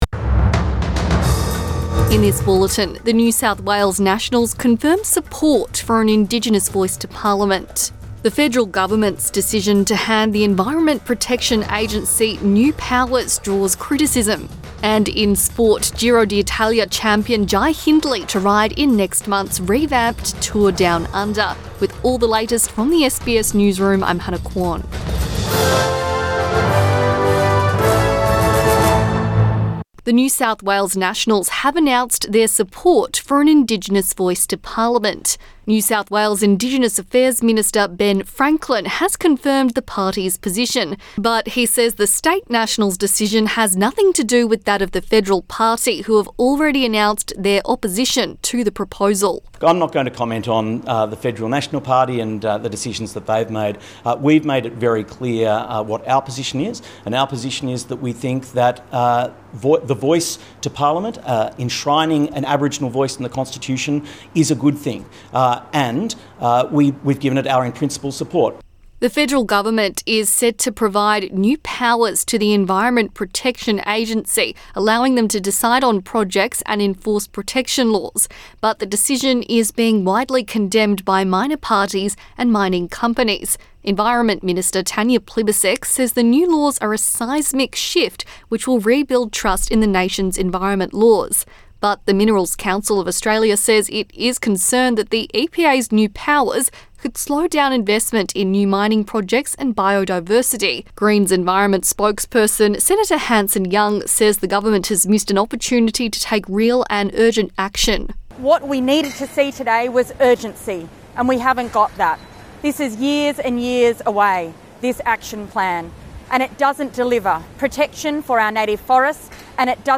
Evening News Bulletin 8 December 2022